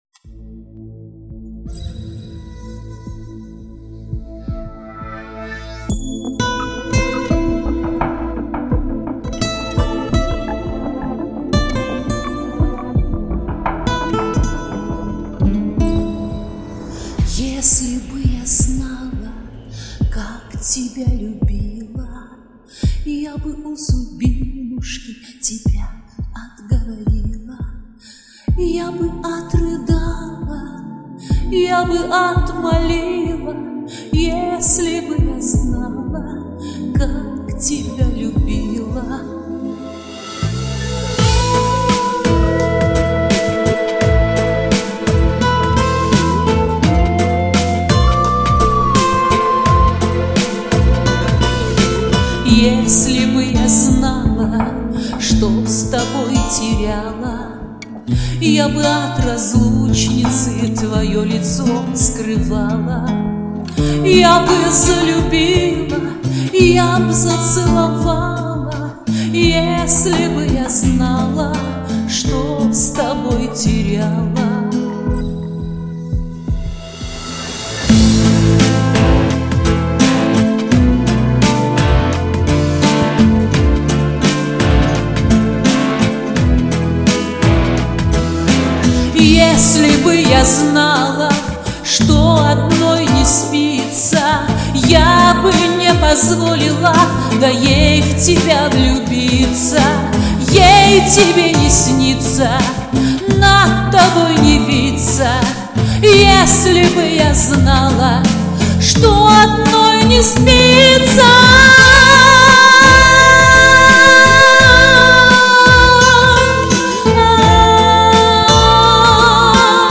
Накал страстей, эмоций! Проникновенное исполнение у обеих!